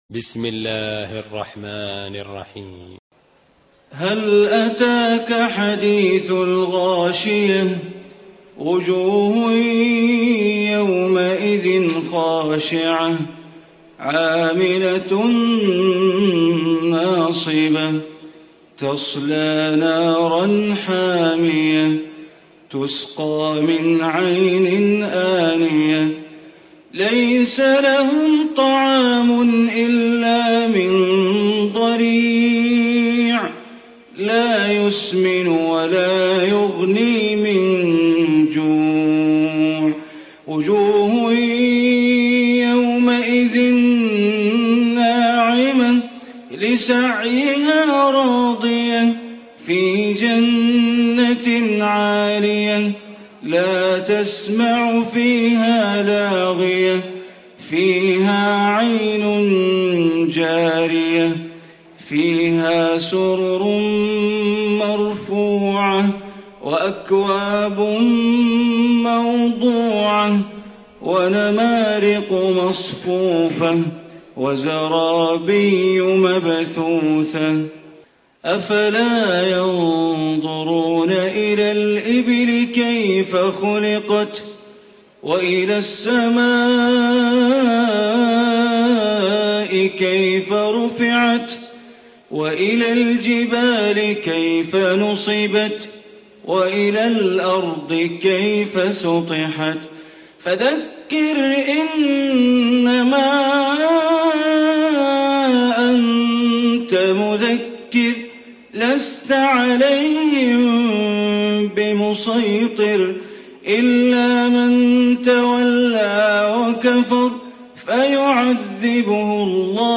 Surah Ghashiyah Recitation by Bandar Baleela
88-surah-ghashiya.mp3